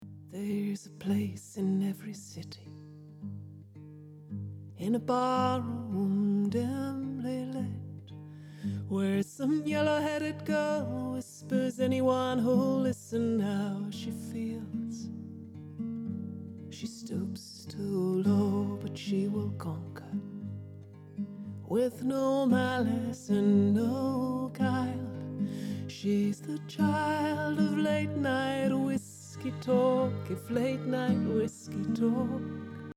et le deuxième exemple en mode wide (fonctionnant donc comme un compresseur avec side-chain classique :
==>>comp/deess en mode wide -- encore un massacre, pourtant aucun réglage n'a changé en dehors du mode de fonctionnement !